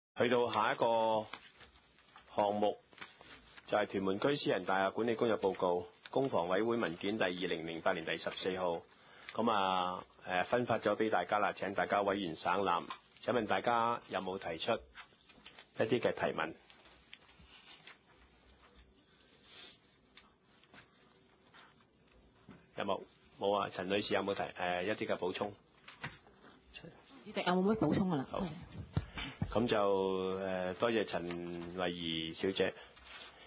屯門區議會會議室